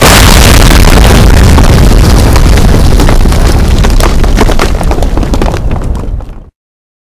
clap_1.ogg